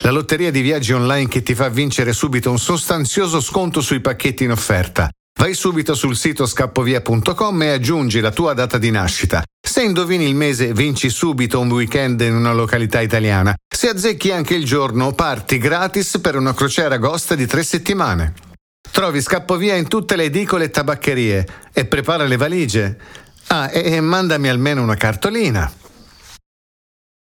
Voiceover in Italian for commercials and videos
Kein Dialekt
Sprechprobe: Werbung (Muttersprache):